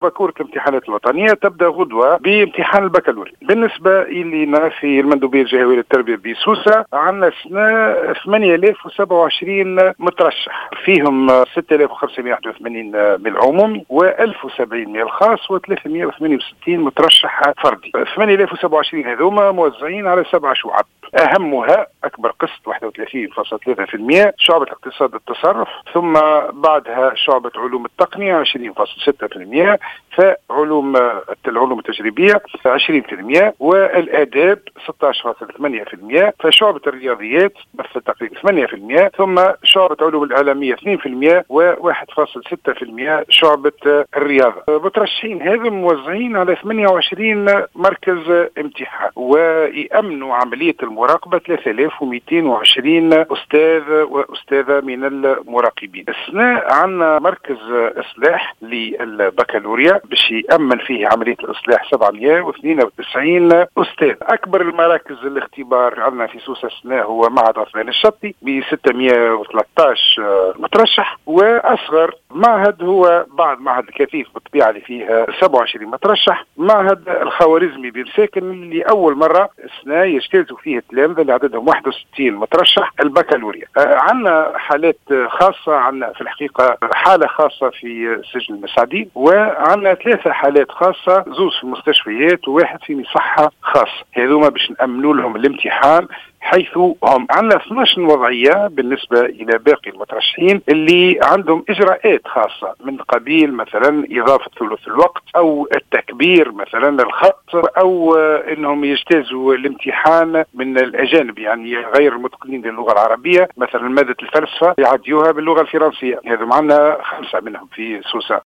وأشار الزبيدي في تصريح للجوهرة أف أم أن6581 تلميذا مترشحا عن المعاهد العمومية و 1070 عن المعاهد الخاصة و368 مترشحا بصفة فردية .